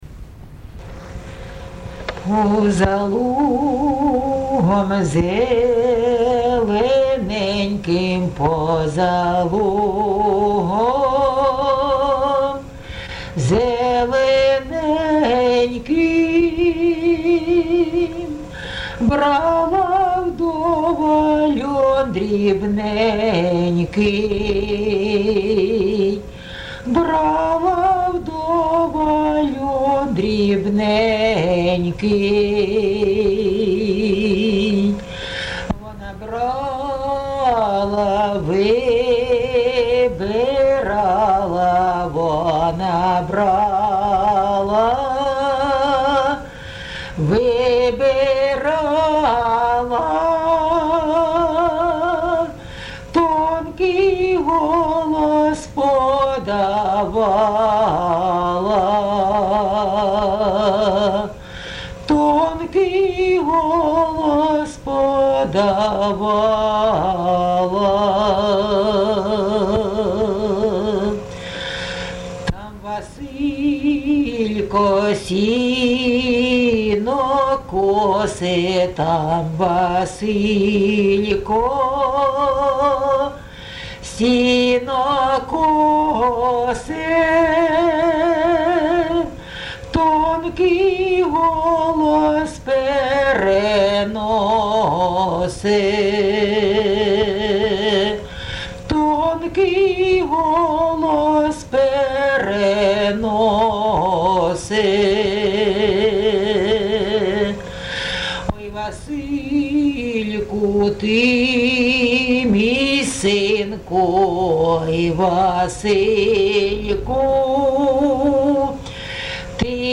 ЖанрПісні з особистого та родинного життя
Місце записус. Михайлівка, Олександрівський (Краматорський) район, Донецька обл., Україна, Слобожанщина